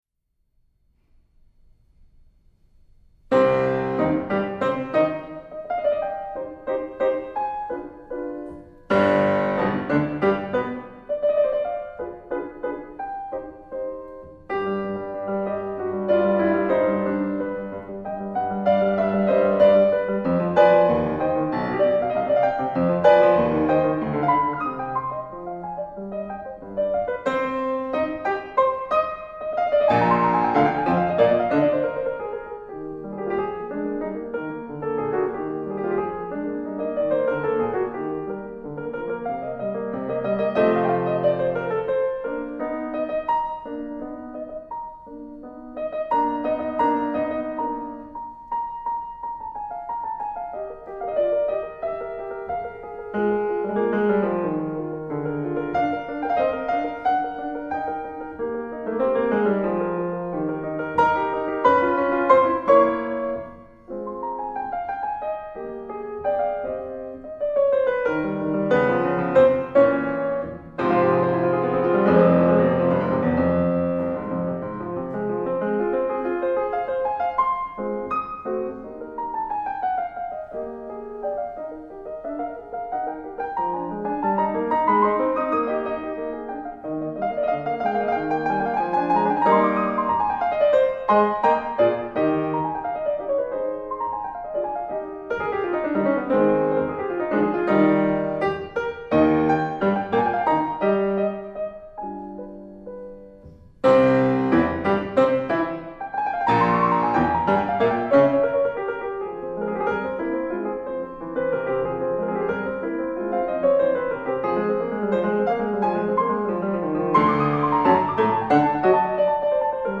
A sampling of my solo and chamber music recordings: